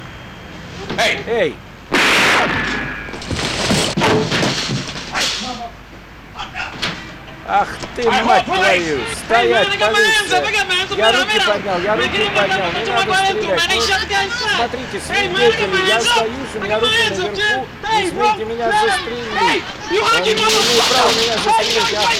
Если в данный момент есть голос переводчика - то уровень оригинального трека либо уменьшается...
На мой слух, при этом Оригинальном канале и синхро переводе, Трэкспэйсер самое то, больше с этим возиться смысла нет..